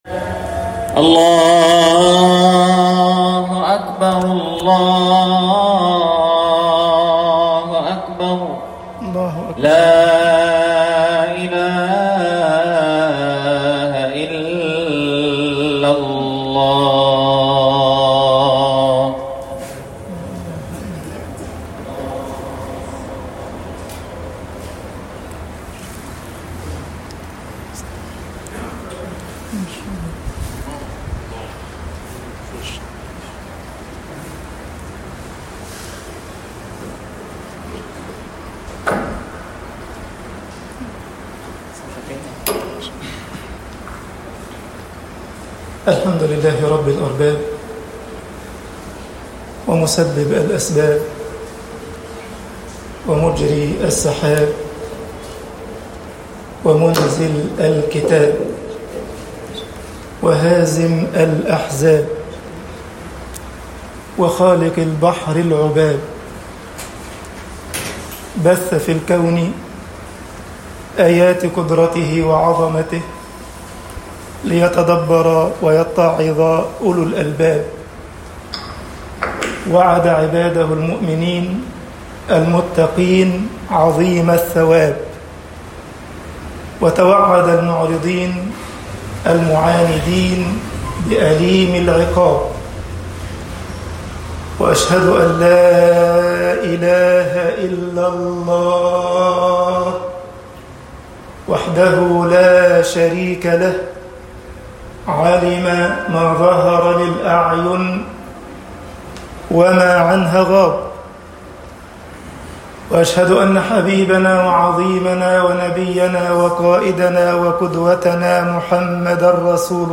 خطب الجمعة - مصر أجمع آية في مكَارِم الْأخْلاقِ طباعة البريد الإلكتروني التفاصيل كتب بواسطة